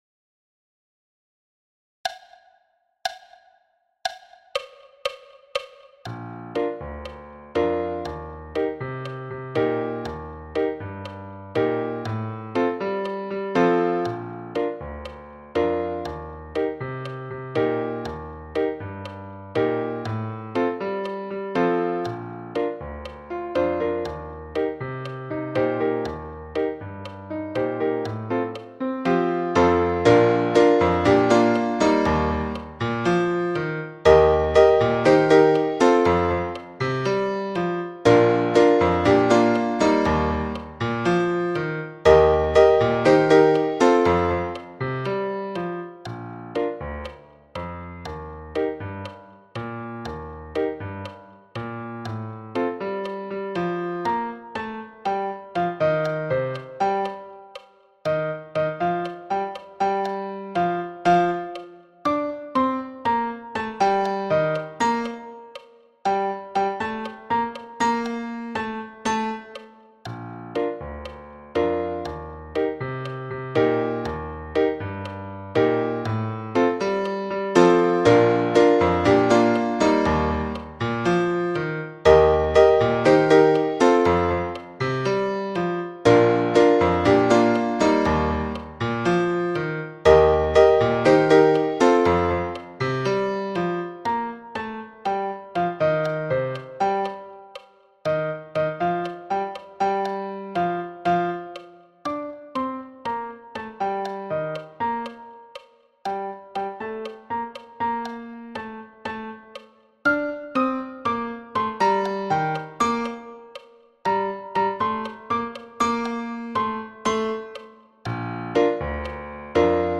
Rudimental rhumba – piano à 120 bpm